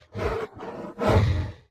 Minecraft Version Minecraft Version 25w18a Latest Release | Latest Snapshot 25w18a / assets / minecraft / sounds / mob / polarbear / idle1.ogg Compare With Compare With Latest Release | Latest Snapshot